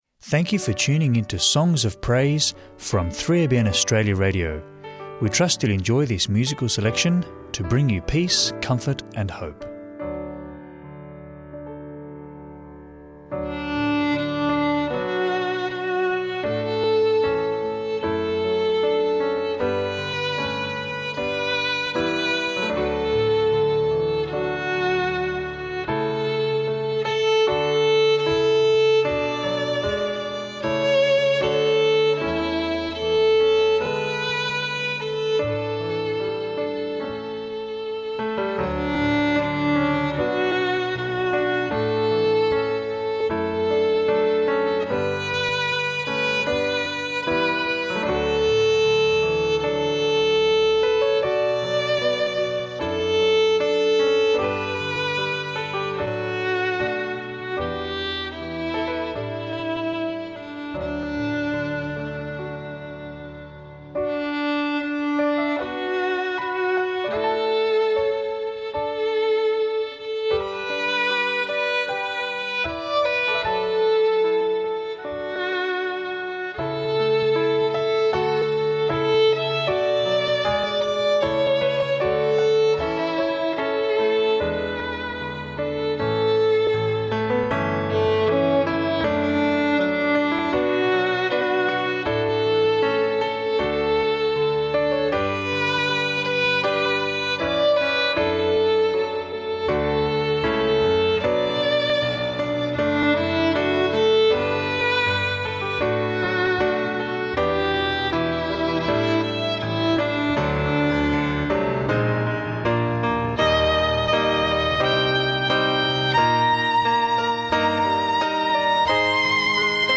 uplifting Christian hymns and worship music
Book Reading